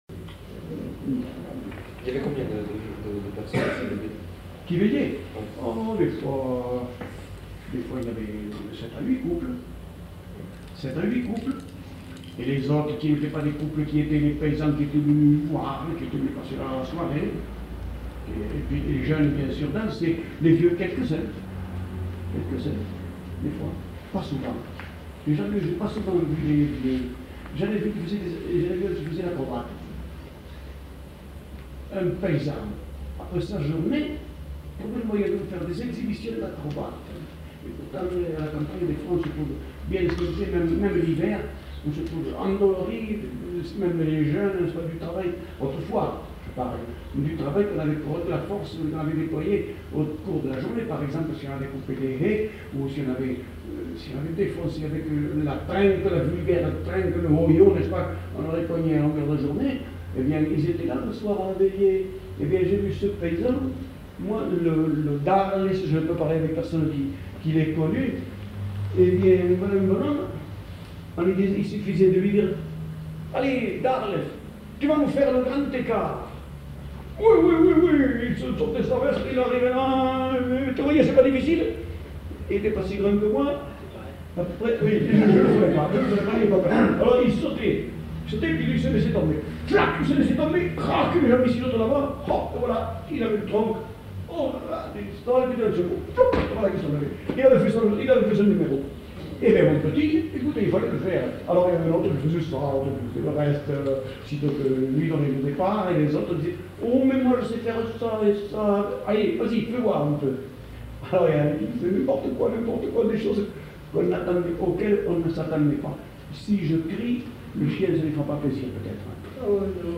Lieu : Foulayronnes
Genre : récit de vie